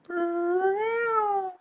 meow8.wav